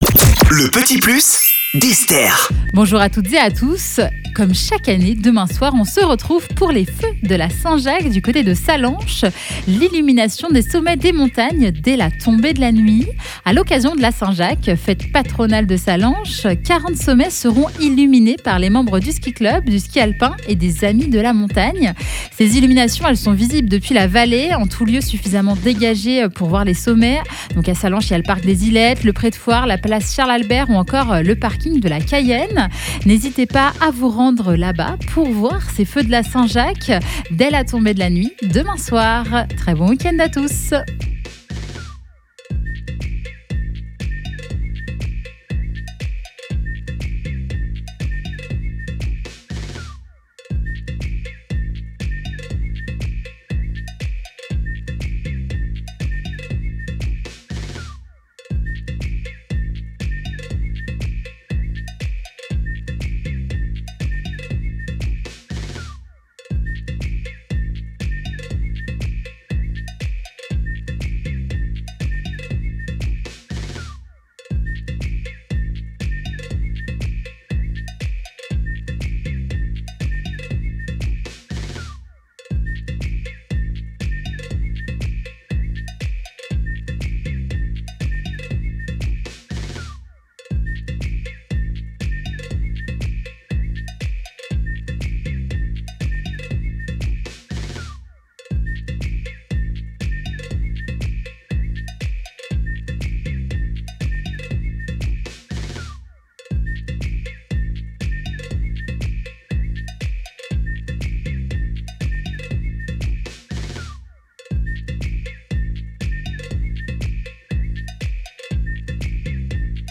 20 - 35 ans